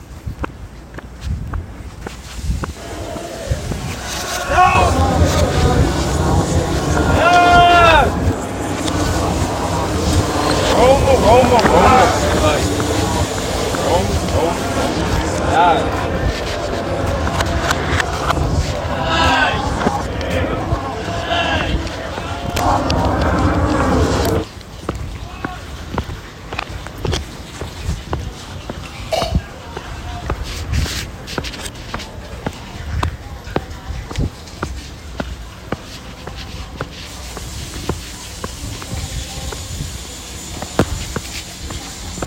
What you’re hearing now are the sounds of my daily routine, from brushing my teeth to walking to class, all presented in the order they occur. Layered within this is the same routine reorganized and played in reverse, symbolizing how constant repetition can become mind-numbing, distorting our sense of time and experience